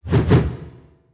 swing_2.wav